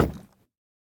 Minecraft Version Minecraft Version snapshot Latest Release | Latest Snapshot snapshot / assets / minecraft / sounds / block / bamboo_wood_hanging_sign / step3.ogg Compare With Compare With Latest Release | Latest Snapshot
step3.ogg